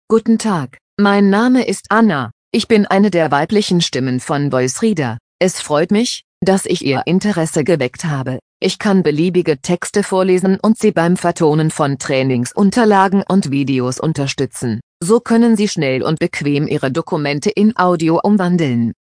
Voice Reader Home 22 Deutsch - Weibliche Stimme [Anna] / German - Female voice [Anna]
Voice Reader Home 22 ist die Sprachausgabe, mit verbesserten, verblüffend natürlich klingenden Stimmen für private Anwender.